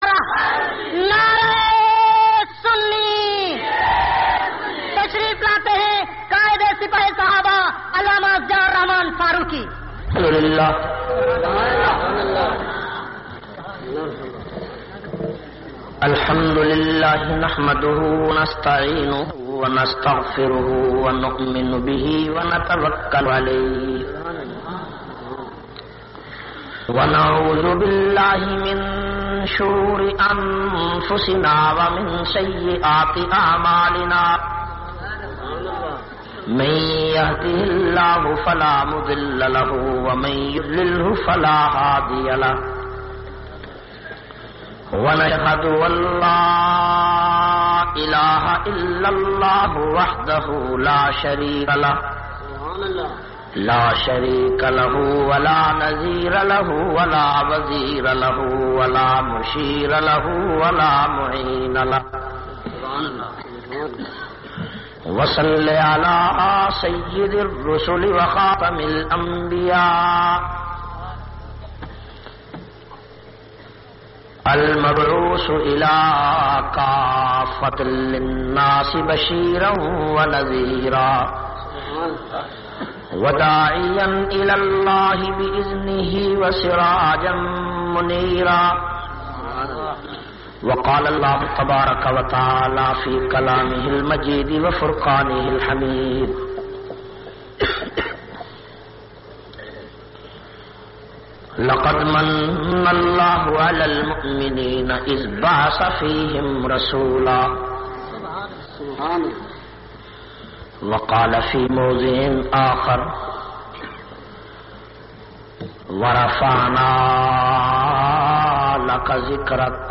336- Tajdar e Madina Conference Jatoi Muzzafargarh.mp3